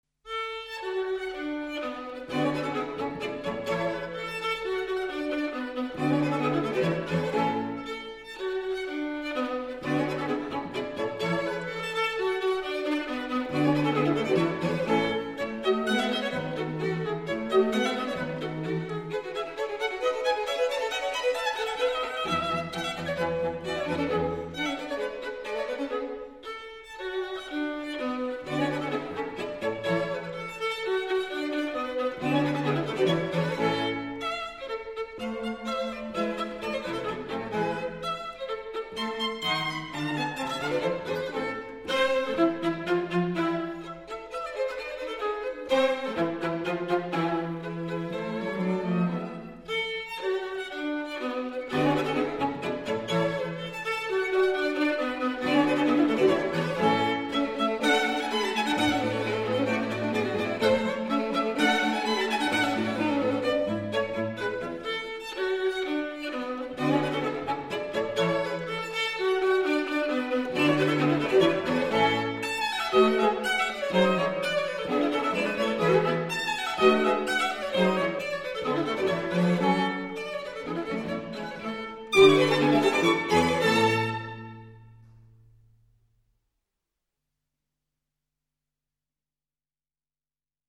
String Quartet in A major
Rondeau. Allegro